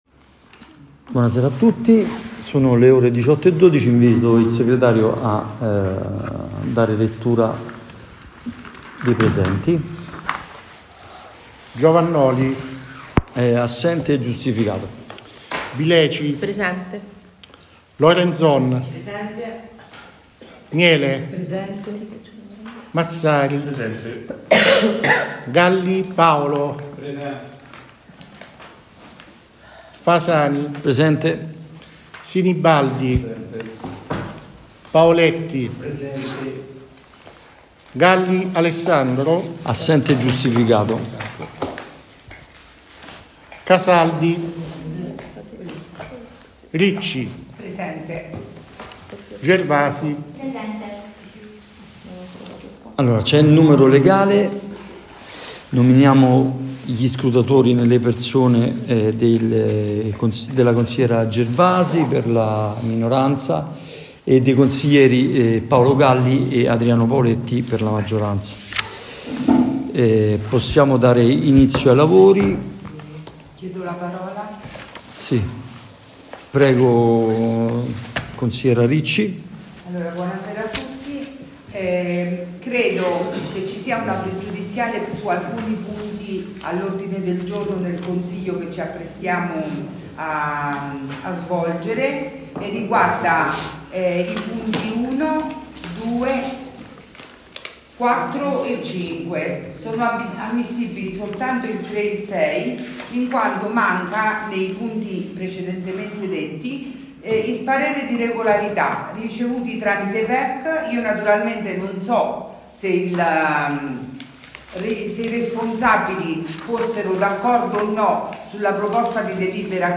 Ascolta le registrazioni audio dei precedenti Consigli Comunali del Comune di Labico: puoi scaricarle come file mp4.